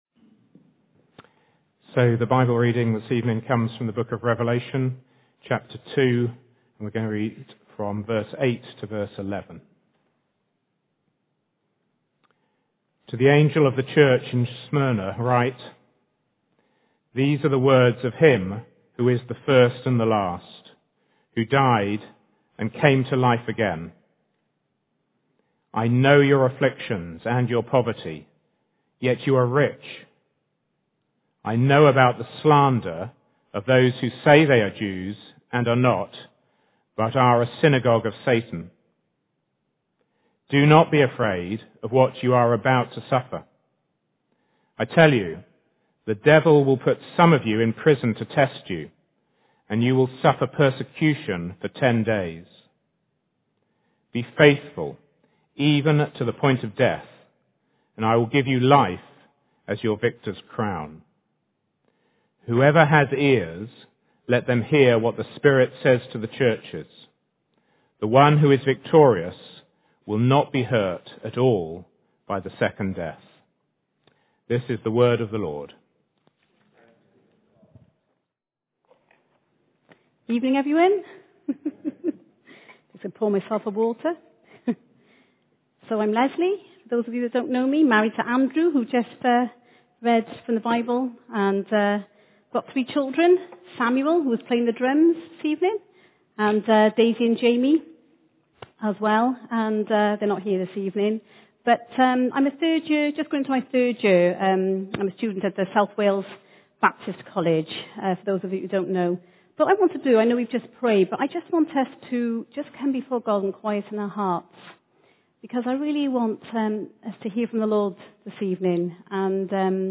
Genre: Speech.